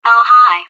potatOS voice